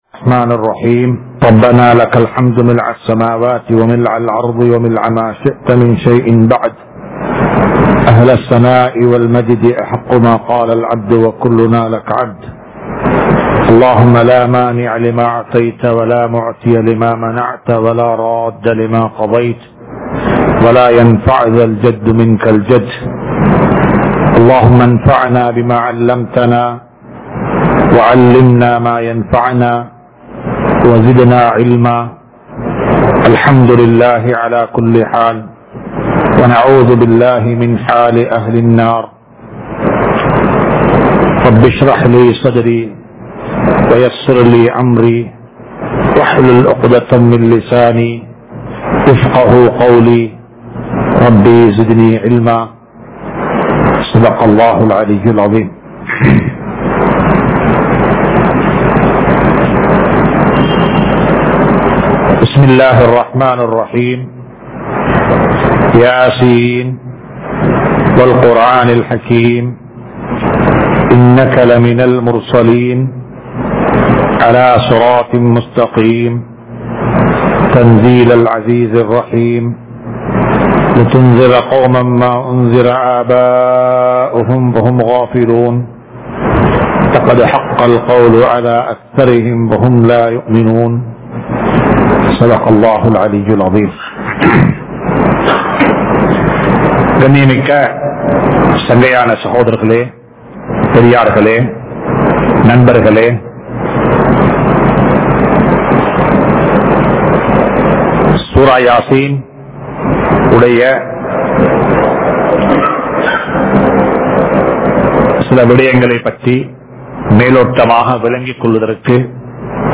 Sura Yasin(Thafseer Lesson 172) | Audio Bayans | All Ceylon Muslim Youth Community | Addalaichenai
Majma Ul Khairah Jumua Masjith (Nimal Road)